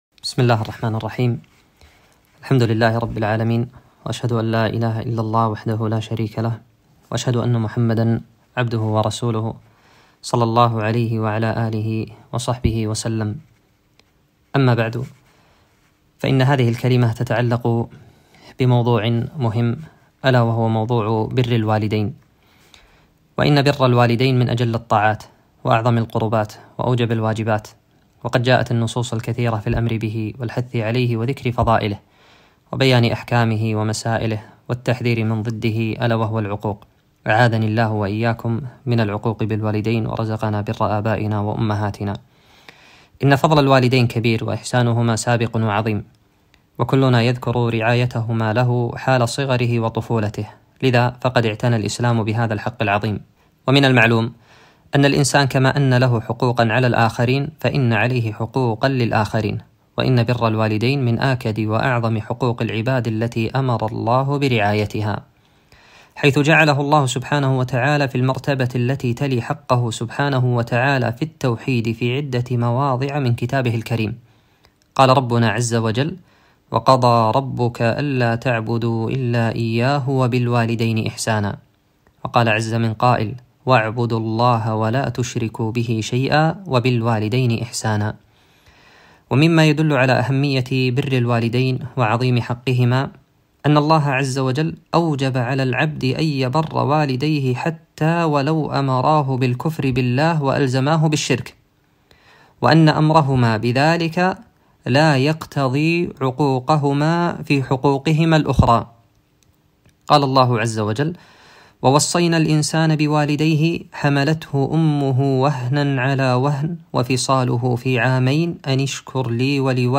محاضرة - برُّ الوالدين